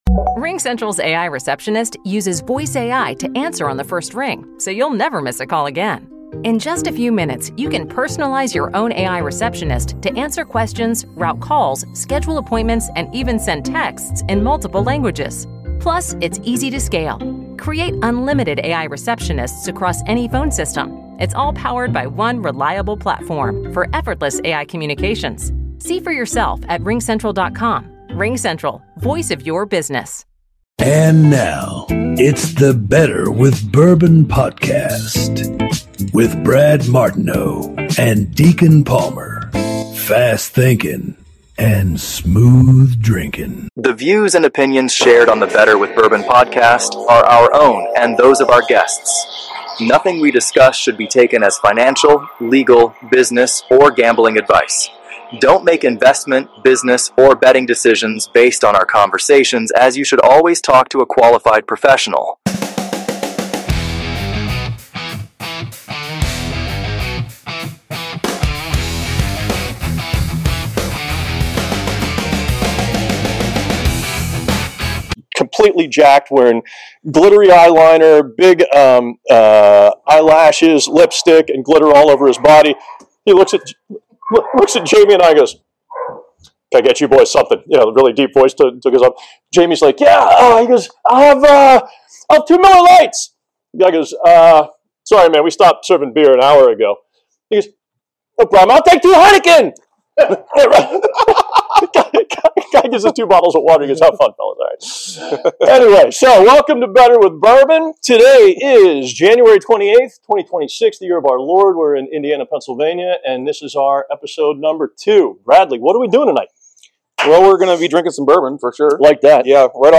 The voice overs are original AI generated voices. And the 'Better with Bourbon' theme music is an original instrumental song that was created using AI.